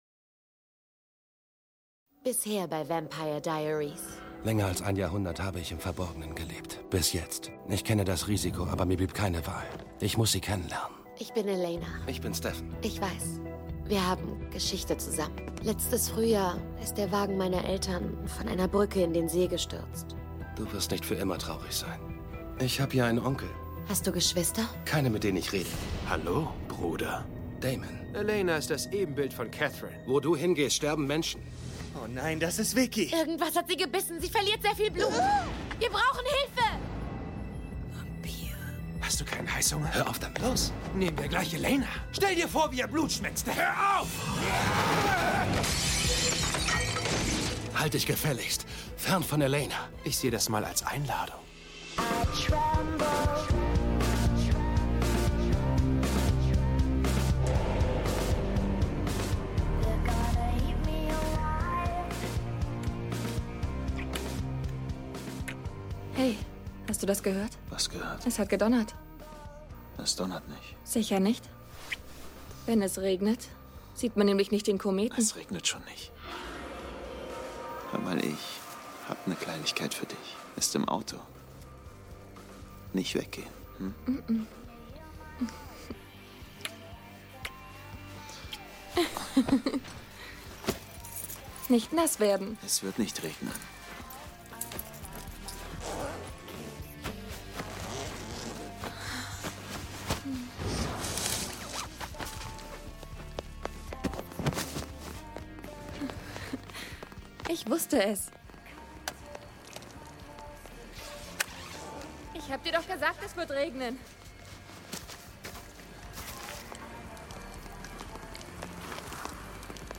S01E02: Die Nacht der Kometen - Vampire Diaries Hörspiel